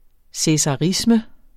Udtale [ sεsɑˈʁismə ]